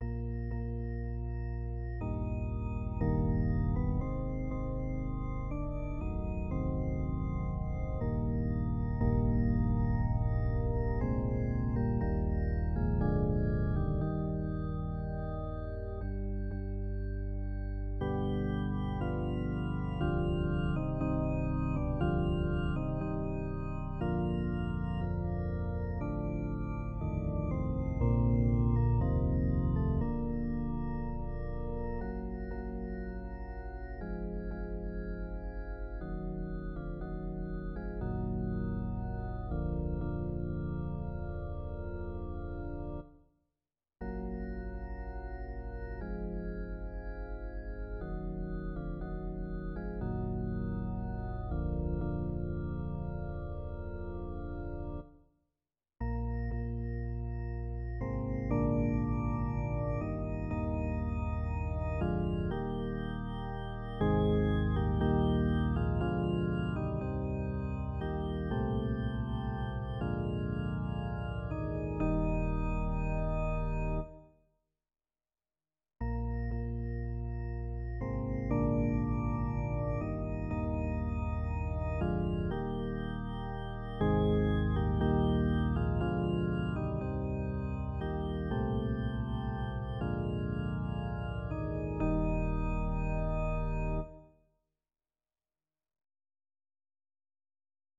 Vegyes karra